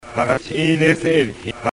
(mp3)というリバース・スピーチが現れていた。